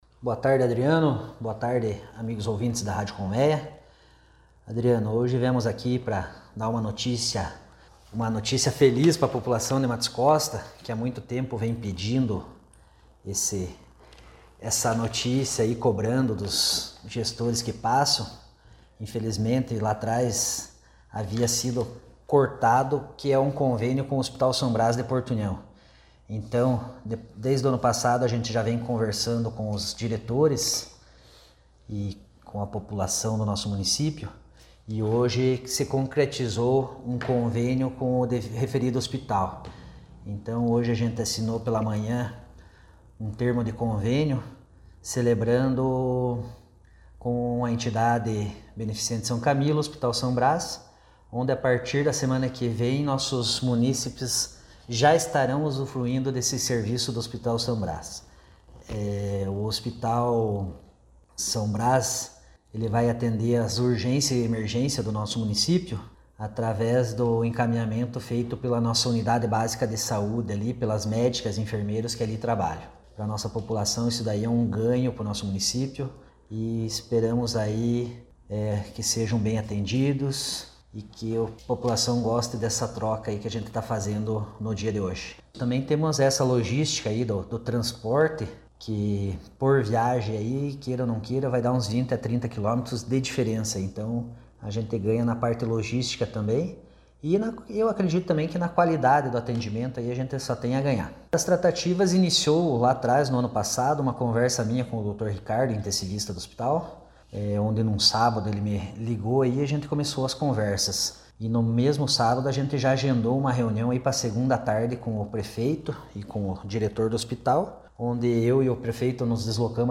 Você pode acompanhar a entrevista completa com o secretário de Saúde de Matos Costa abaixo:
DALTON-FAGUNDES-SEC-SAUDE-MATOS-COSTA.mp3